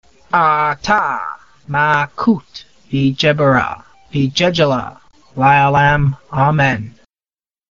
pronunciation audio file